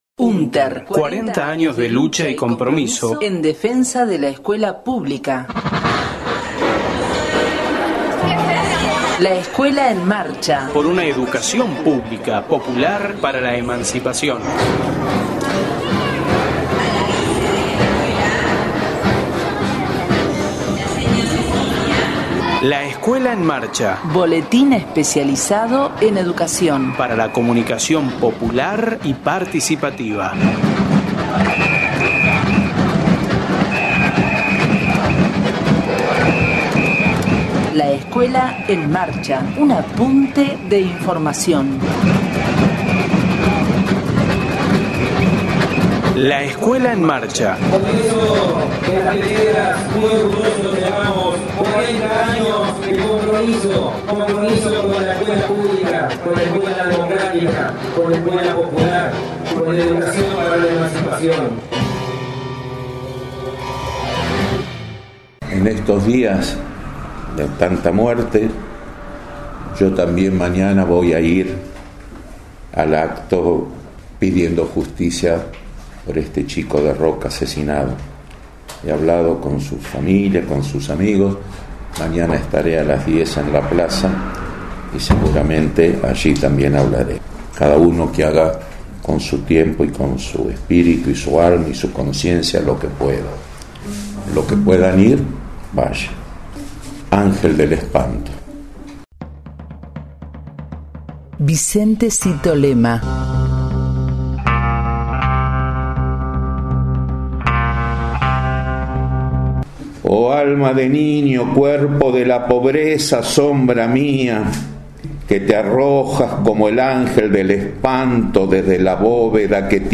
Voz del abogado y poeta